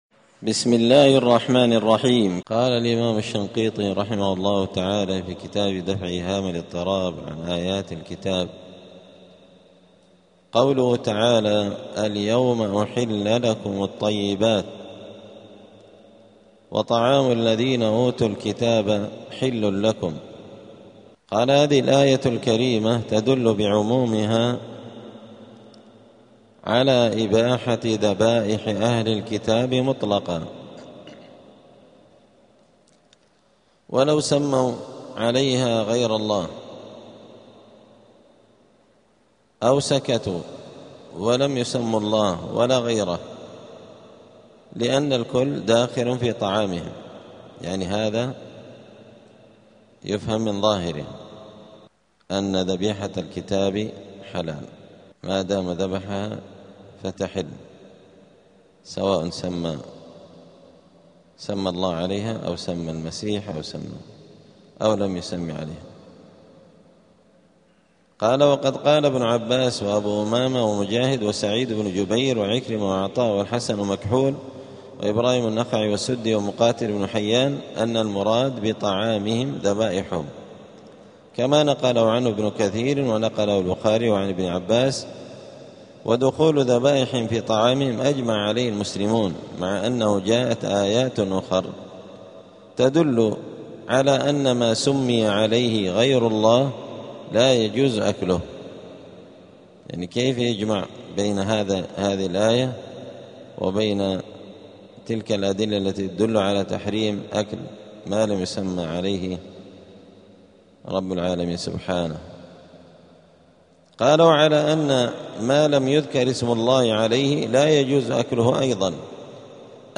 *الدرس الثامن العشرون (28) {سورة المائدة}.*